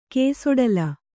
♪ kēsoḍala